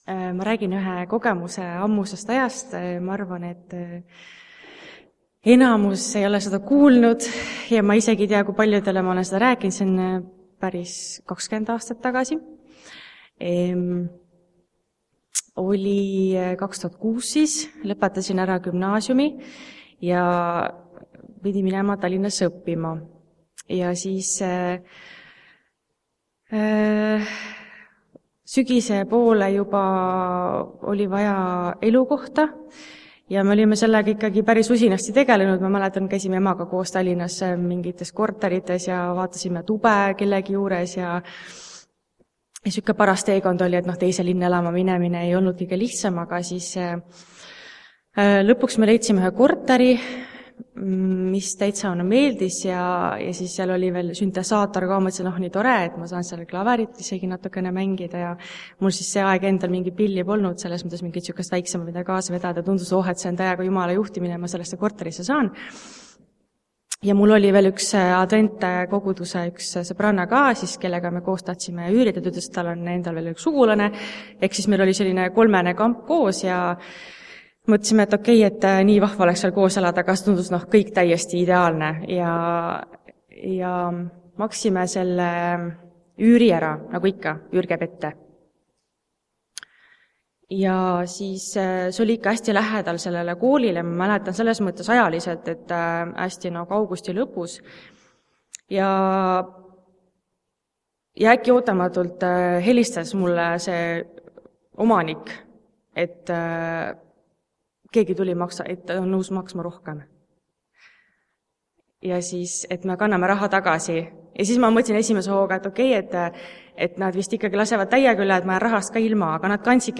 TUNNISTUSTE KOOSOLEK
Jutlused